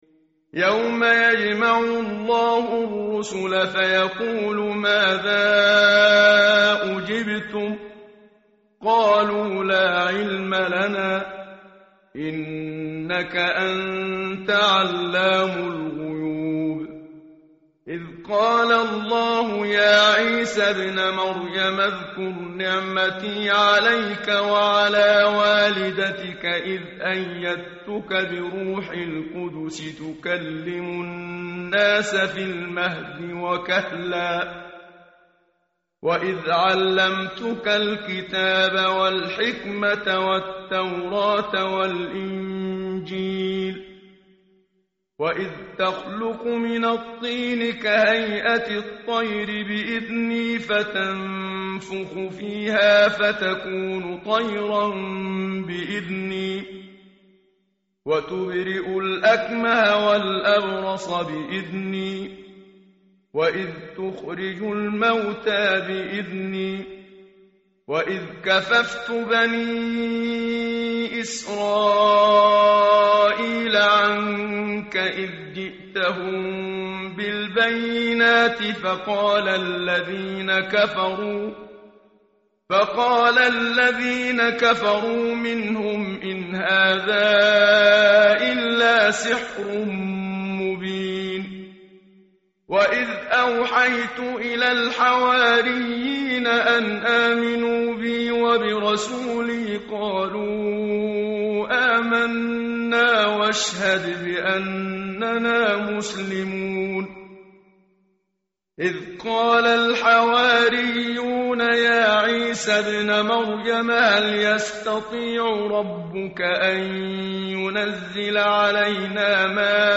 متن قرآن همراه باتلاوت قرآن و ترجمه
tartil_menshavi_page_126.mp3